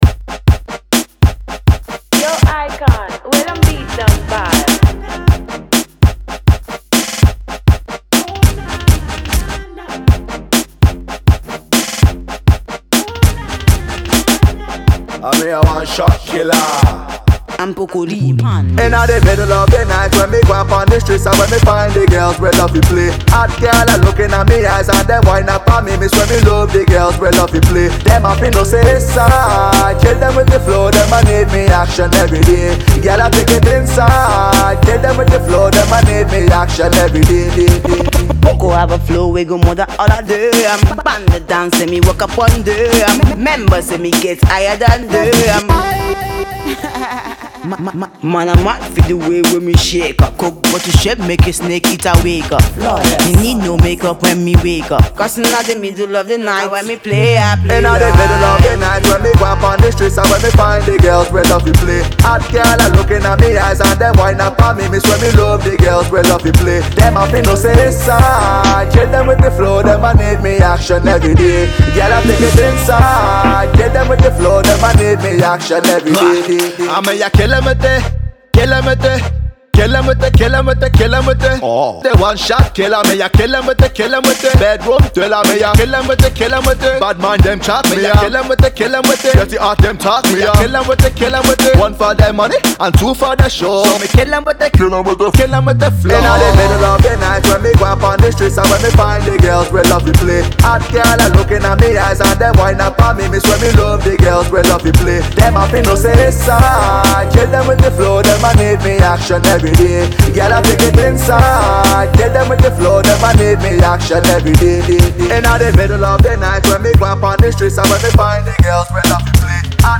Dancehall
A Spanking Dancehall Banger